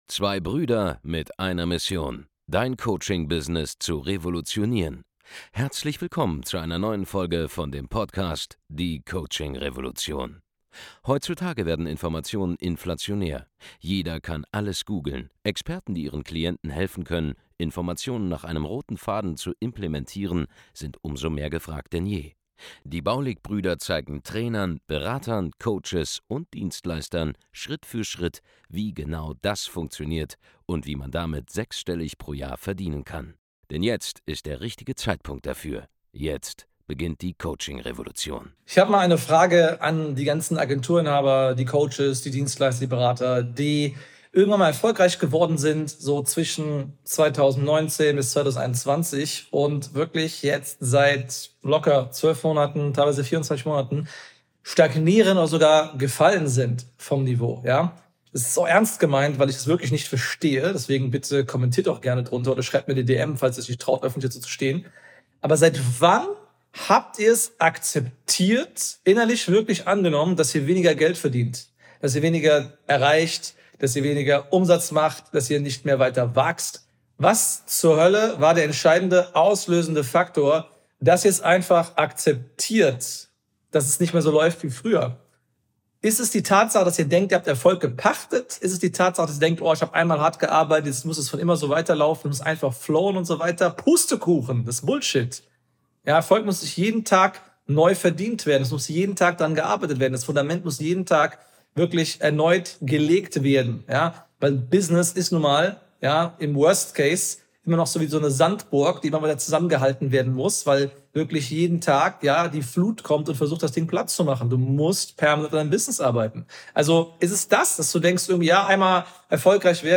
im Rahmen einer Q&A-Session auf einem unserer internen Seminare